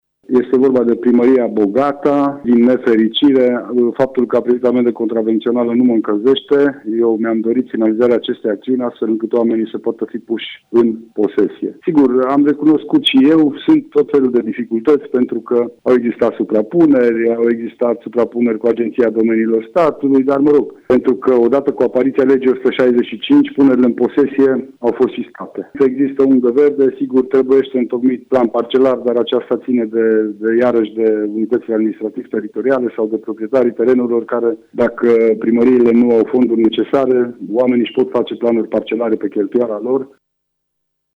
Goga a explicat că întârzierile s-au datorat unor probleme birocratice.